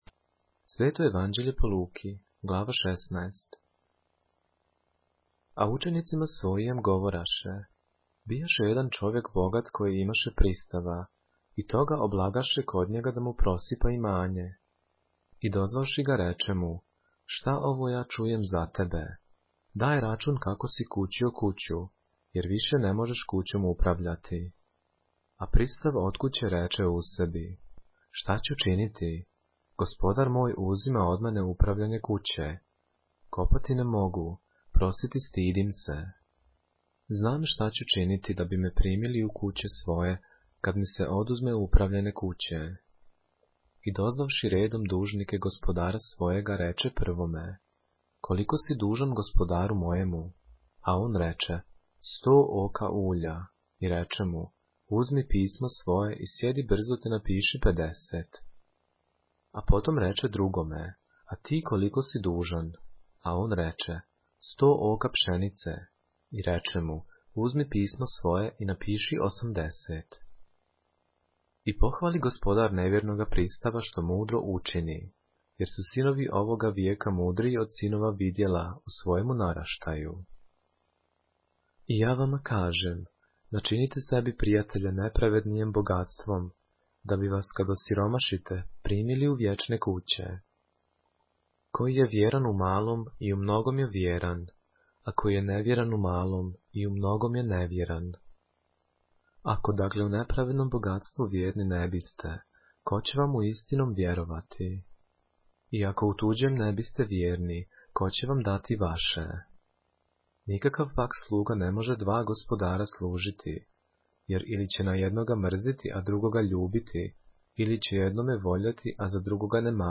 поглавље српске Библије - са аудио нарације - Luke, chapter 16 of the Holy Bible in the Serbian language